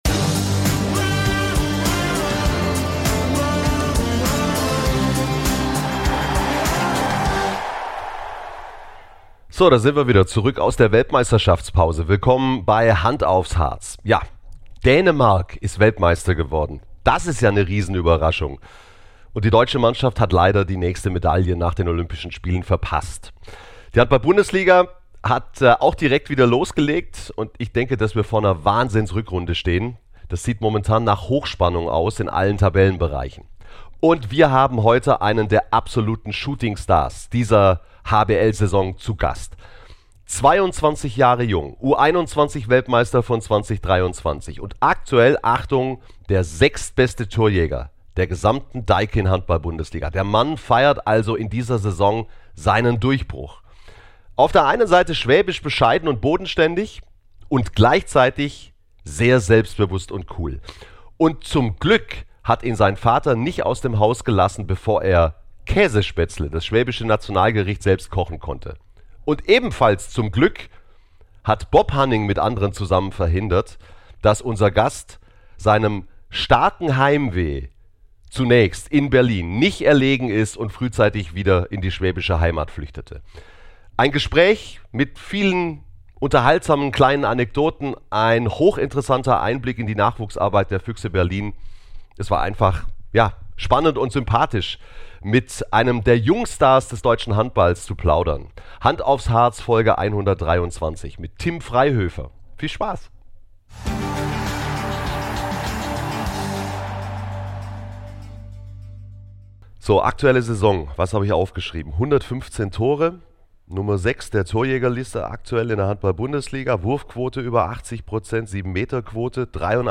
Spannende Insider-Informationen und tiefgründige Interviews mit den besten Gästen aus dem Handballsport